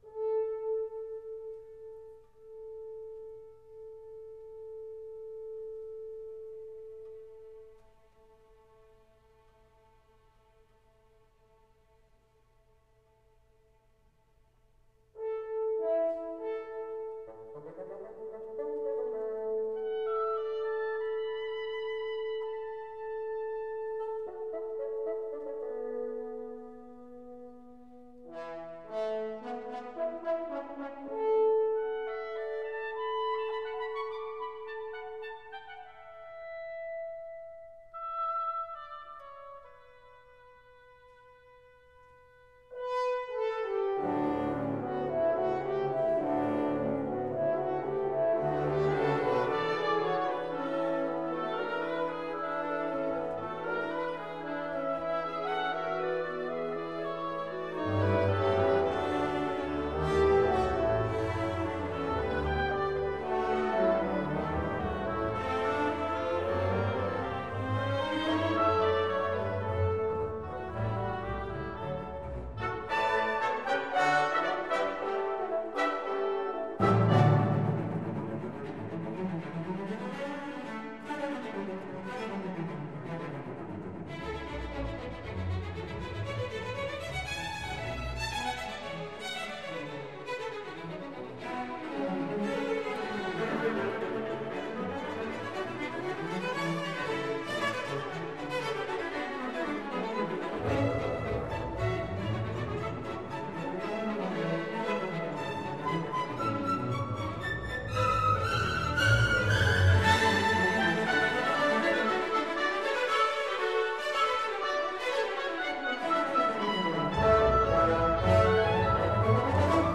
Rondo-Finale. Allegro - Allegro giocoso.